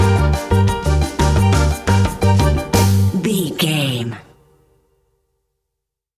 An exotic and colorful piece of Espanic and Latin music.
Aeolian/Minor
flamenco
romantic
maracas
percussion spanish guitar
latin guitar